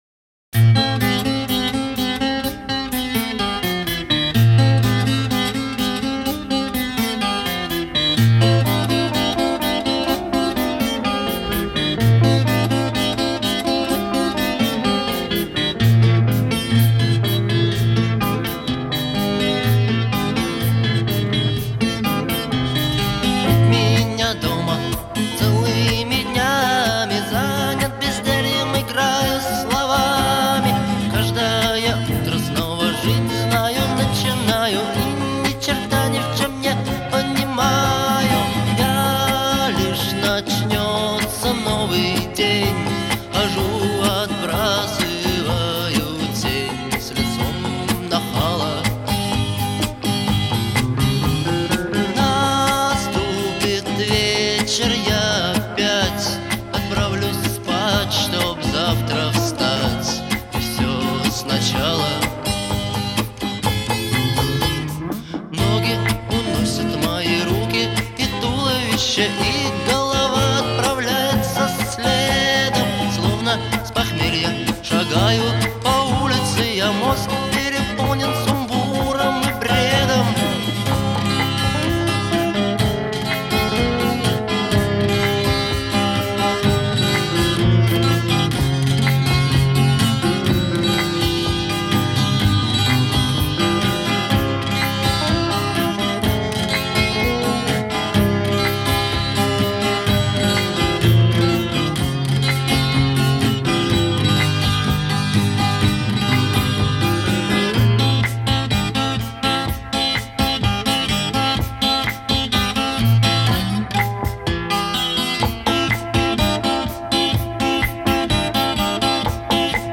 характерной гитарной мелодией
эмоциональным вокалом